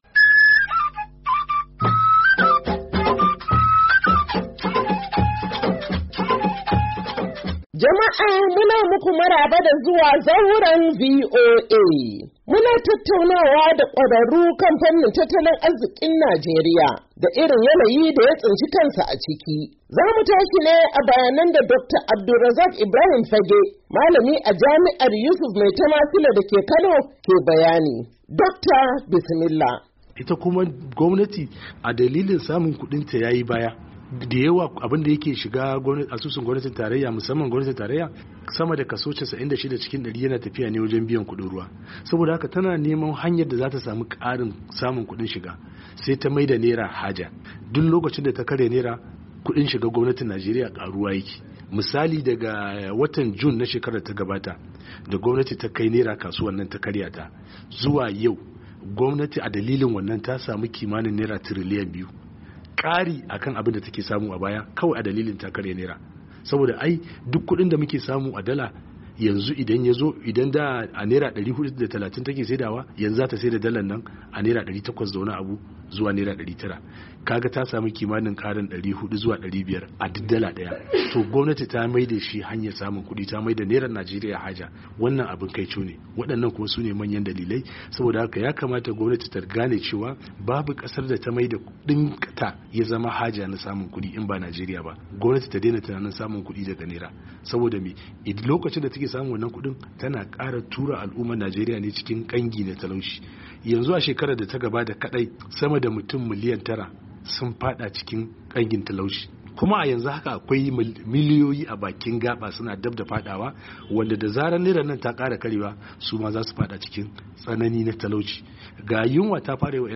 Shirin Zauran VOA, ya tattauna da masana tattalin arziki, inda suka bai wa Shugaban Najeriya Bola Ahmed Tinubu, shawara yadda za'a ingata tattalin arzikin kasar cikin gaggawa, sakamakon yadda mutane suke ci gaba da kokawa kan yadda farashin kayayyaki ke ci gaba da tashi gwauran zabi a kasar.